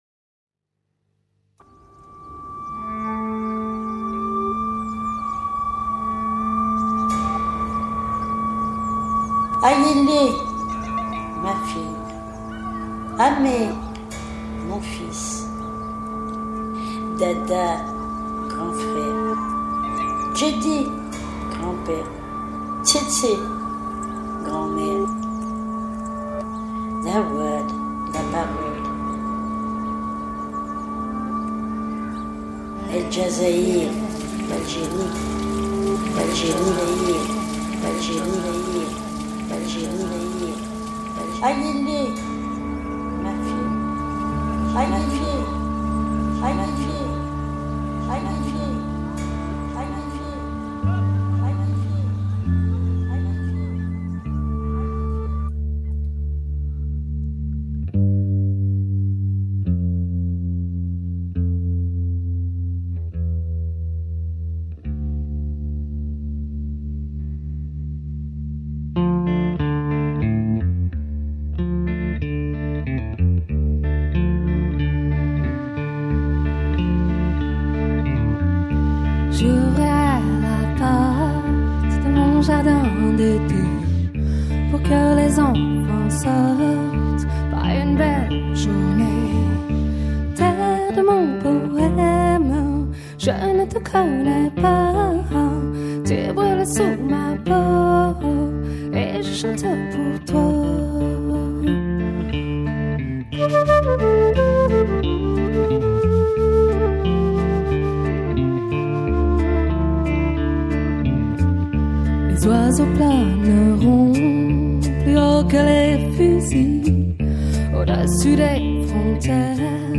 Sounds from Algeria mixed with Czech Republic tonight
The world does a mashup and the results are intoxicating.